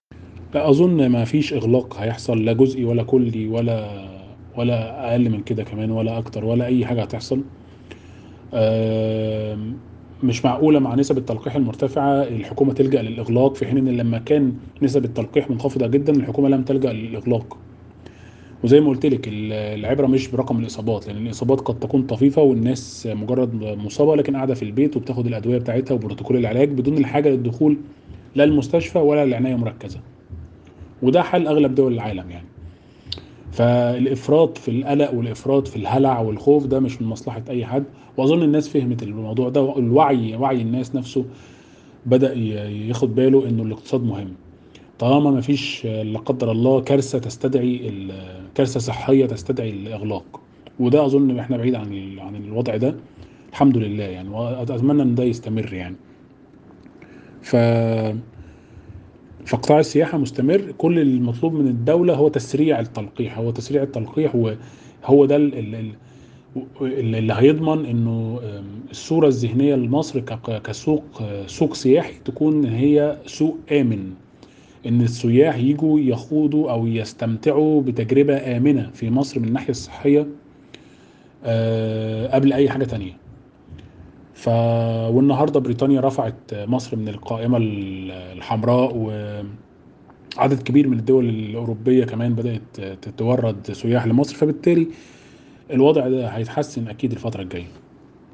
حوار
محلل اقتصادي